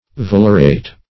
valerate - definition of valerate - synonyms, pronunciation, spelling from Free Dictionary Search Result for " valerate" : The Collaborative International Dictionary of English v.0.48: Valerate \Val"er*ate\, n. (Chem.) A salt of valeric acid.